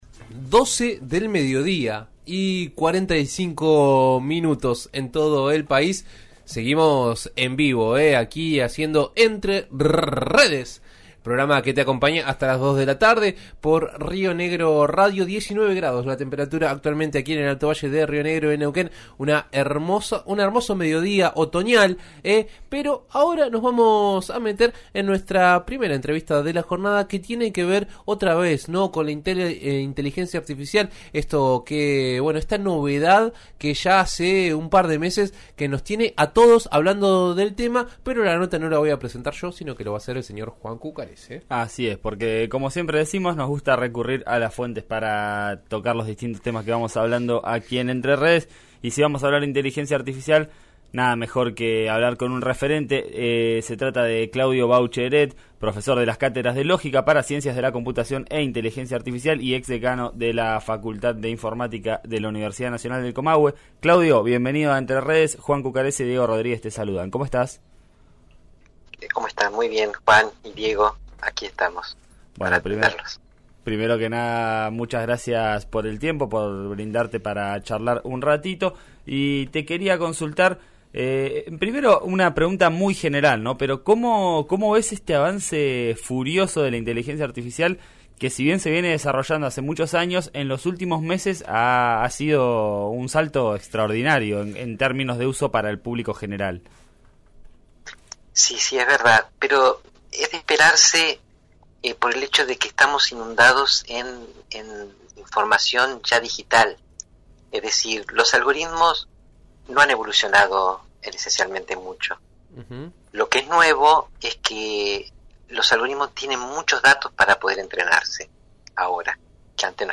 Por eso, habló con RÍO NEGRO RADIO sobre el tema y dejó varios pasajes interesantes.